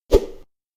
Melee Weapon Air Swing 9.wav